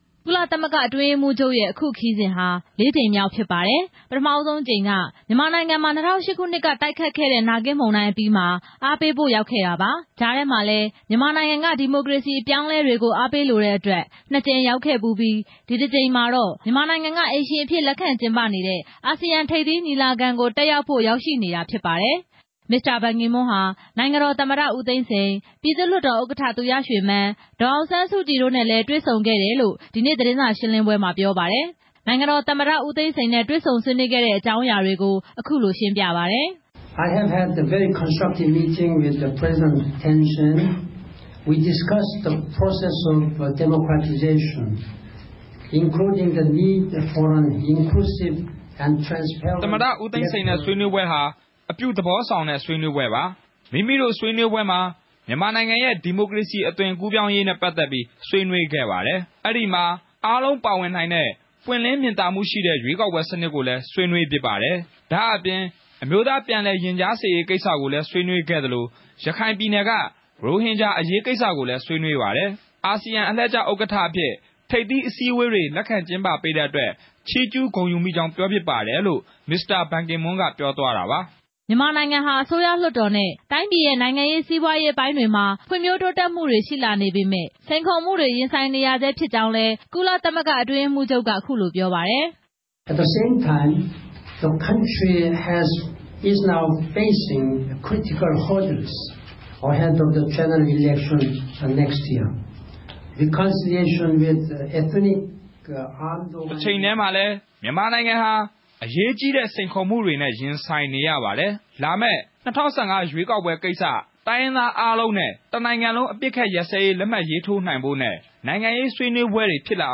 မစ္စတာ ဘန်ကီမွန်း သတင်းစာရှင်းလင်းပွဲ